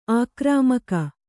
♪ ākramaka